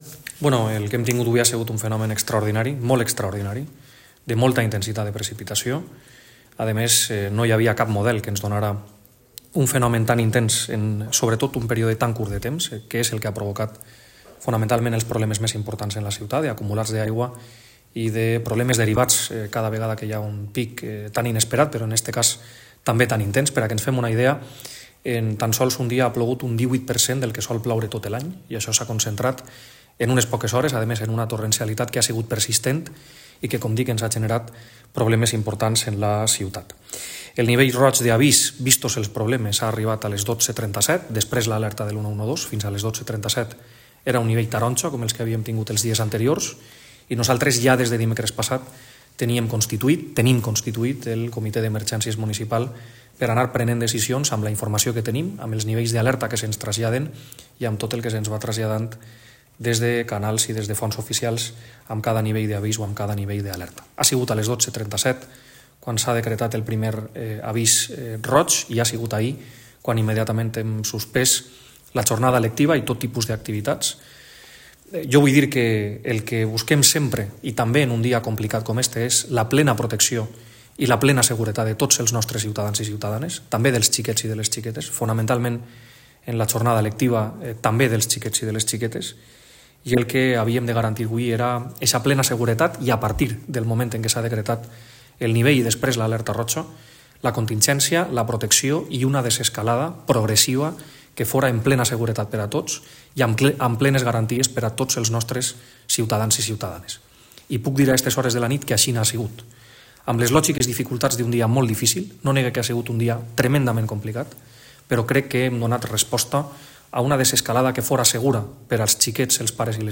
‘Ha sido una situación sobrevenida e inesperada la que hemos vivido esta mañana’ así lo decía el alcalde de Gandia en la comparecencia que ha ofrecido a los medios de comunicación tras la reunión mantenida con el comité de emergencia y comunicar las decisiones a los portavoces municipales.